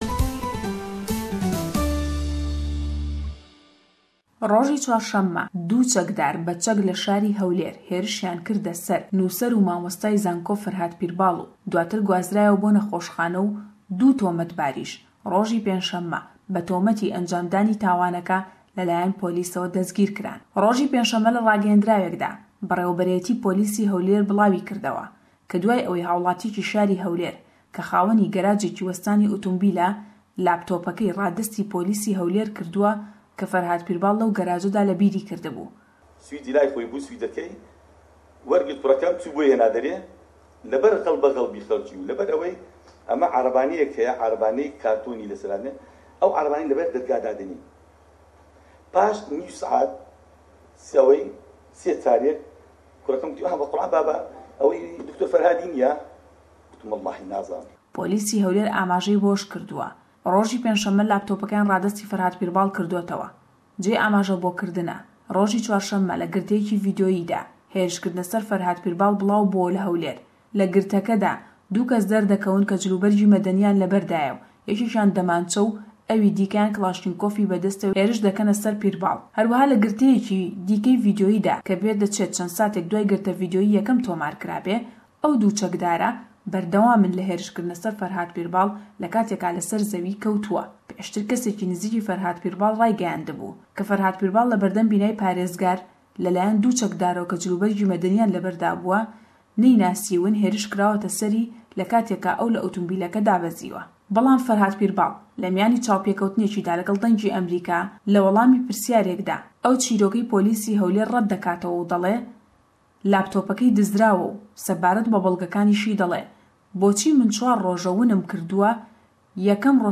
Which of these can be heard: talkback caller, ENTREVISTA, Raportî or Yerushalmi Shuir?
Raportî